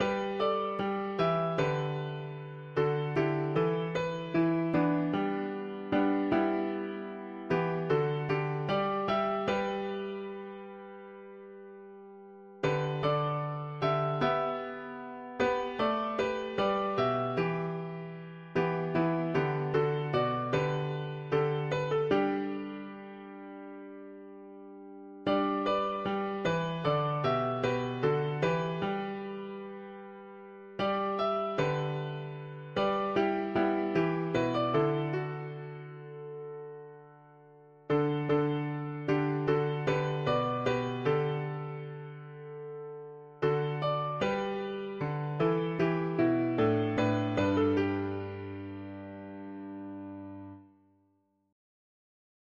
Key: G major